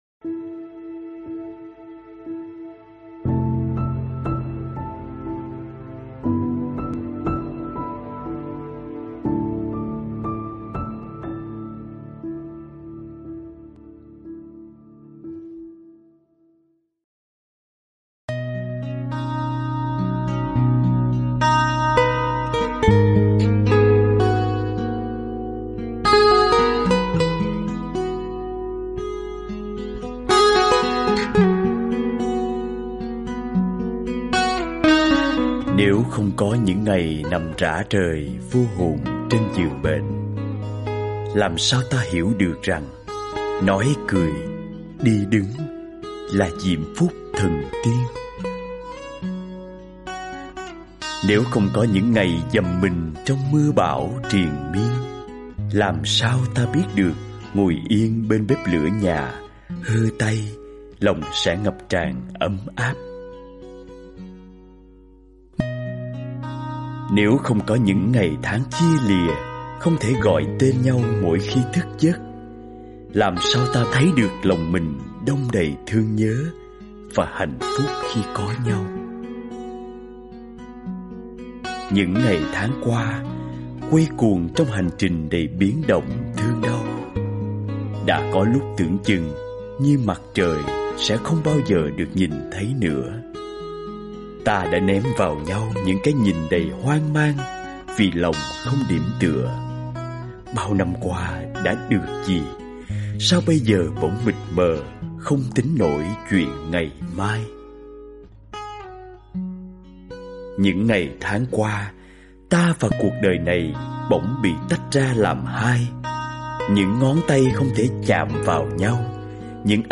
Pháp âm Nếu khổ đau không đến đây